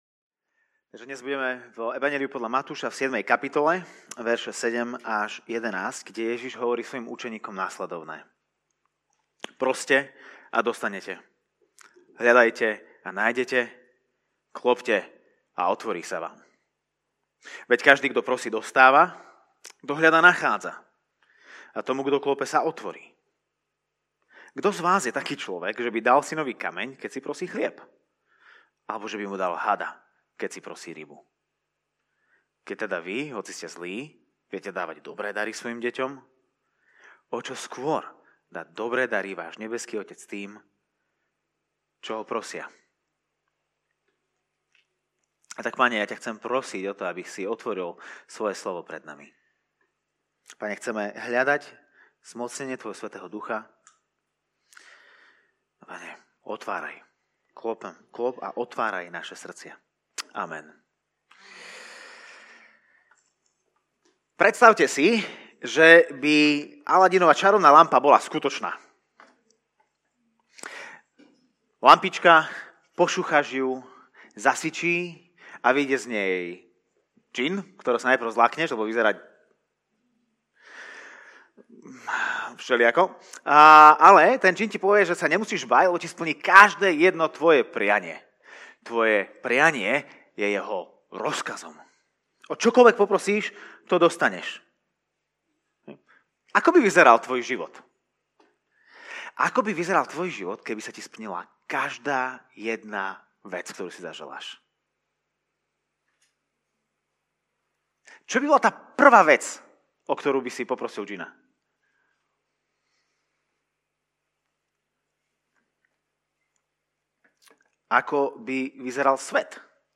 - Podcast Kázne zboru CB Trnava - Slovenské podcasty